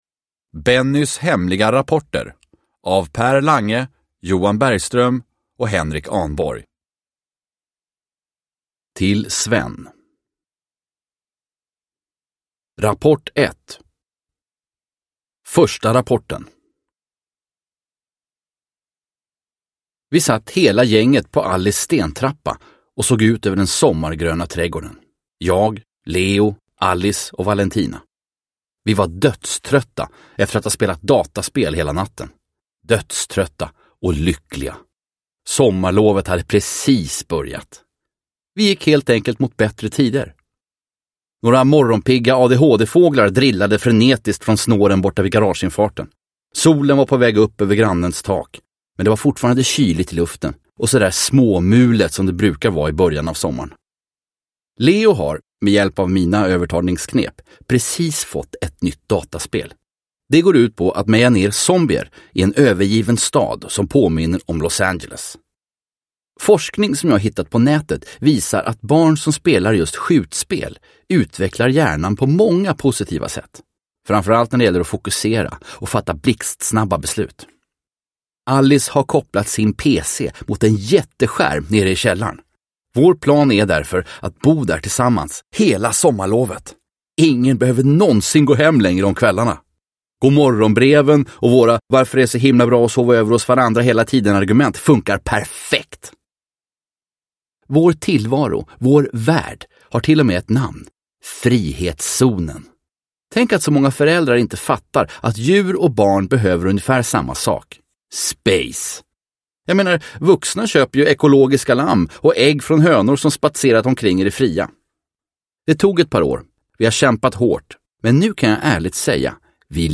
Bennys hemliga rapporter – Ljudbok – Laddas ner
Uppläsare: Fredde Granberg